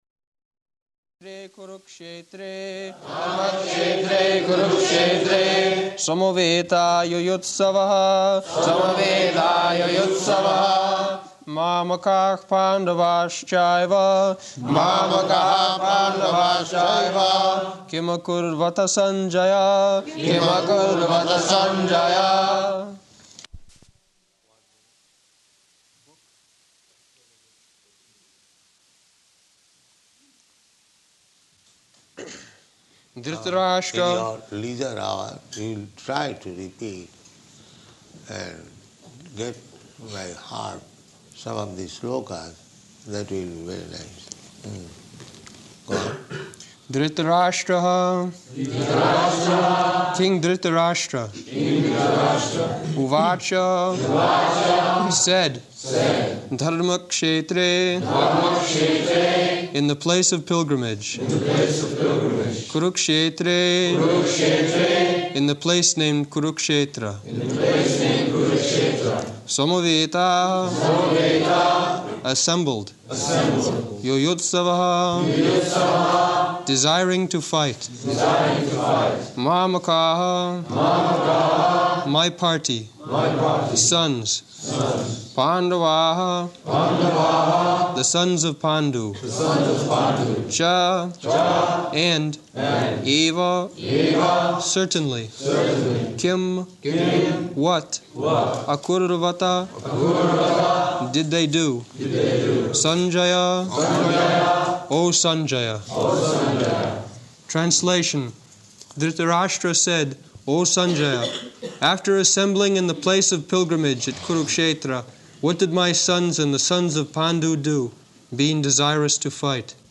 Location: London
[leads chanting of verse] [Prabhupāda and devotees repeat]